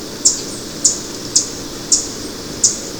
Blackpoll Warbler
VOZ: Un sonoro "chip" es emitido frecuentemente durante la migración.
En los territorios de cría, el macho canta una serie de notas rápidas, todas en el mismo tono agudo. De hecho, el canto es tan agudo que muchas personas no consiguen oírlo.